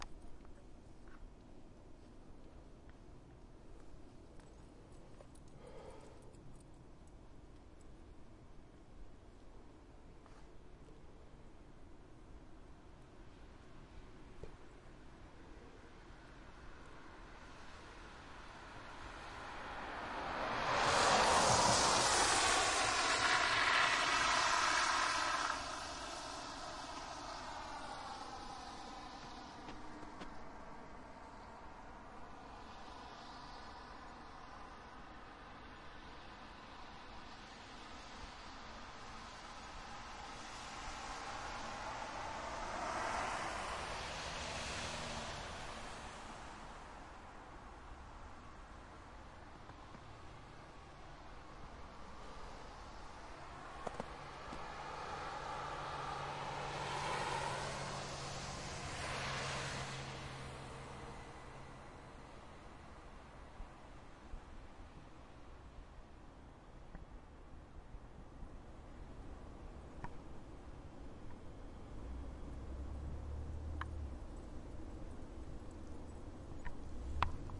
描述：坐在公交车站
Tag: 环境 冬天的时候 费尔德 记录